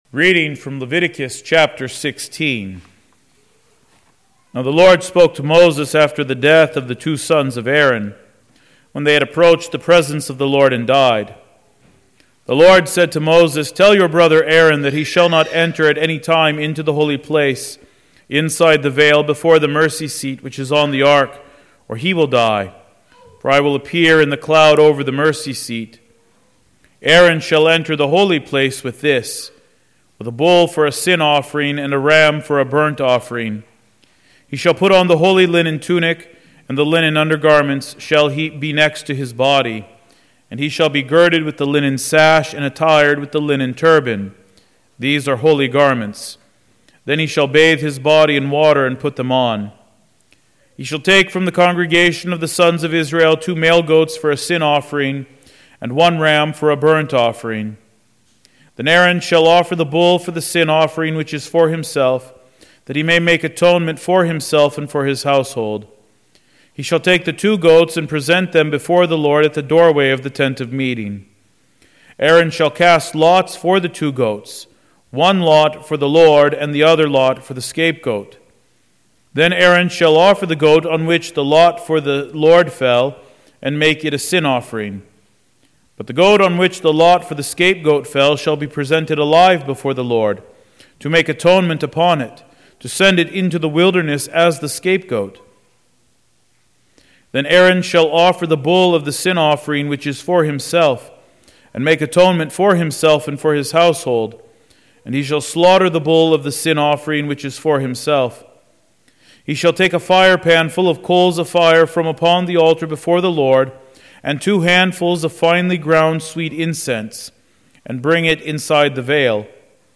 Wednesday, April 24, 2024 (Evening Prayer) - Readings